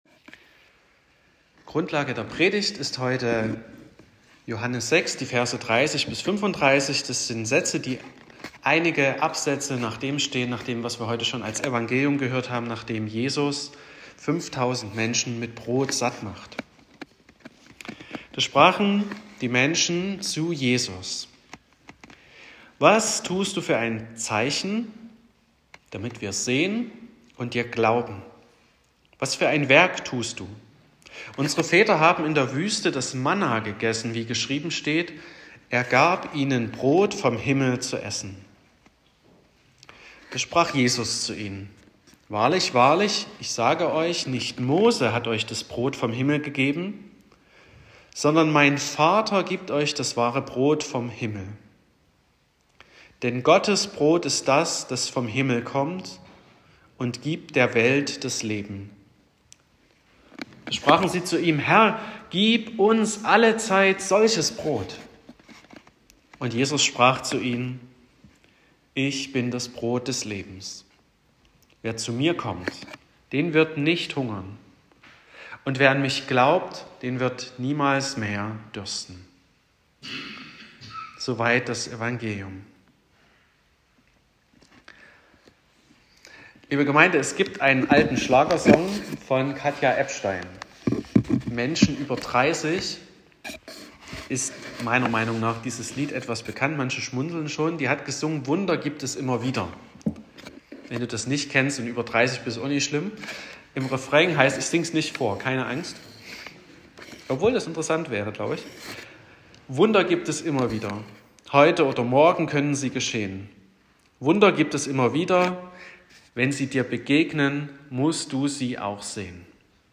03.08.2025 – Gottesdienst
Predigt und Aufzeichnungen